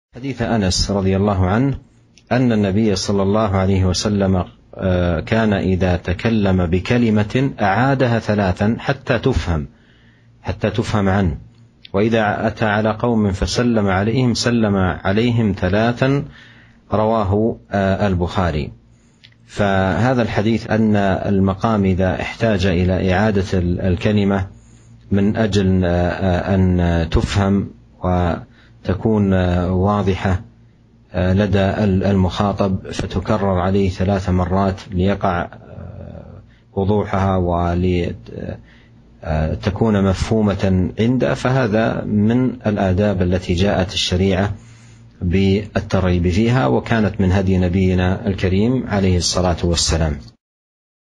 شرح حديث كان ﷺ إذا تكلم بكلمة أعادها ثلاثا حتى تفهم عنه